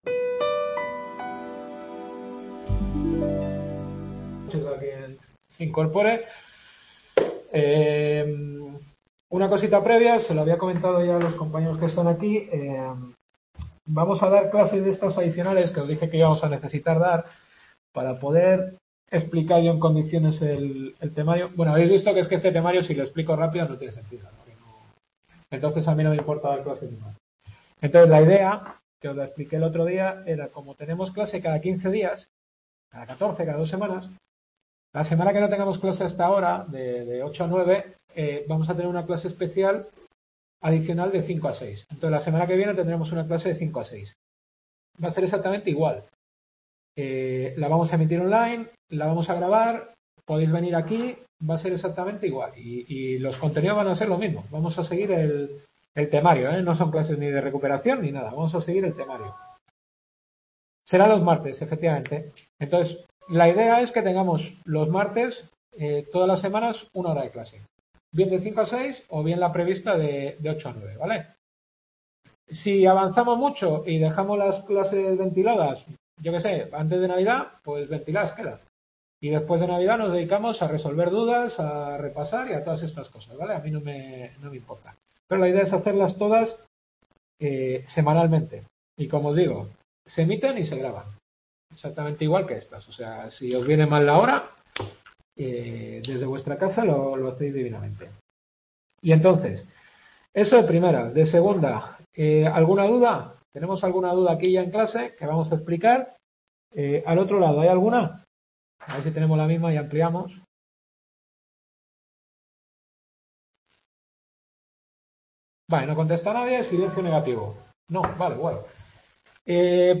Introducción a la teoría del Derecho. Tercera clase…